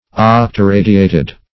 Octoradiated \Oc`to*ra"*di*a`ted\